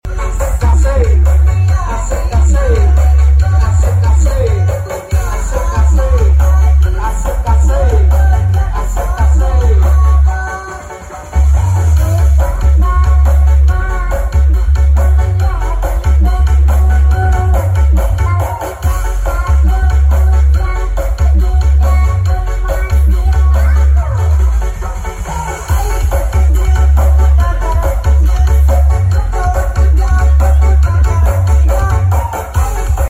Karnaval mojorejo full mening” bolo😻🤪🔥 sound effects free download